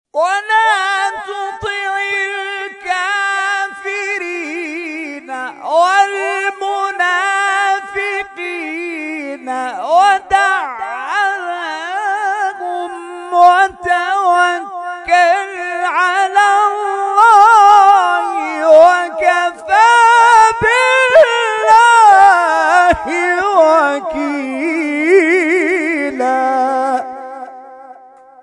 در ادامه قطعات تلاوت این کرسی ها ارائه می‌شود.